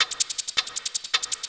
Shaker 03.wav